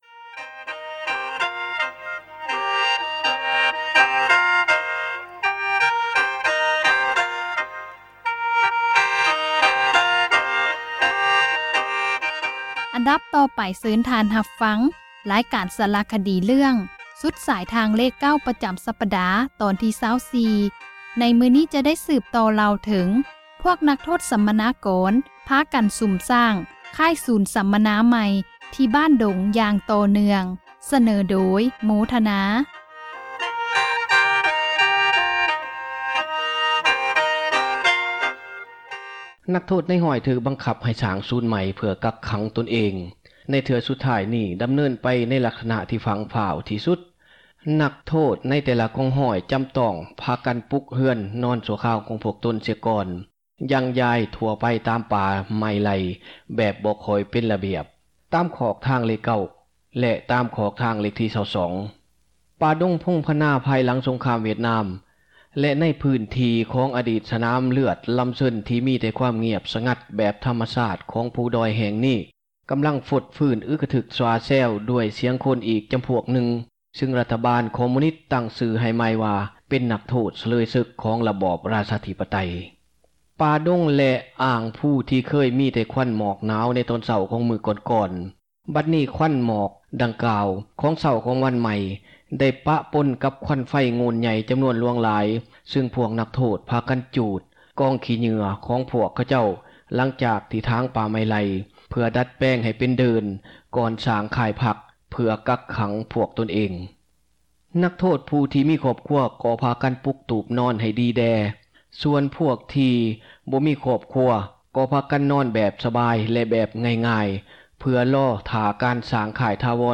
ສາຣະຄະດີ ເຣື້ອງສຸດສາຍທາງເລຂ 9 ຕອນທີ 24 ໃນມື້ນີ້ຈະໄດ້ສືບຕໍ່ ເລົ່າເຖິງພວກນັກໂທດ ສັມມະນາກອນ ພາກັນສຸມສ້າງ ຄ້າຍສັມມະນາກອນໃໝ່ ທີ່ບ້ານດົງ ຢ່າງຕໍ່ເນື່ອງ.